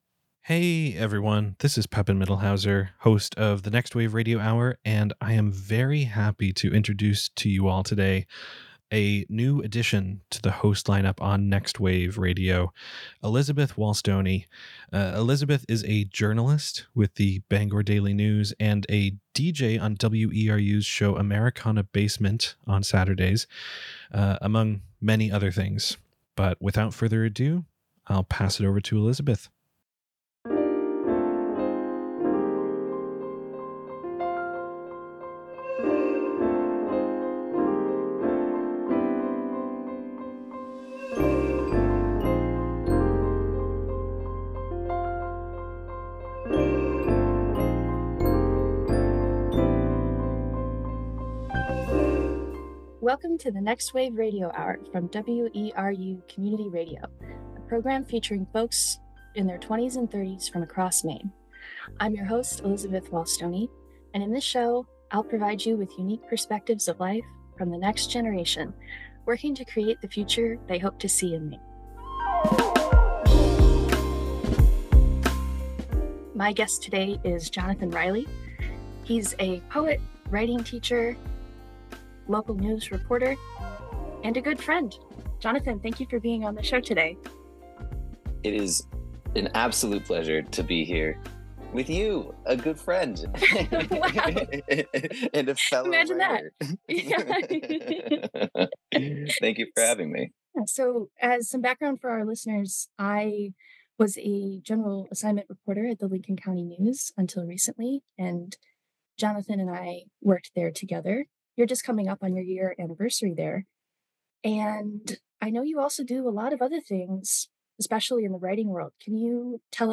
All other music is royalty free.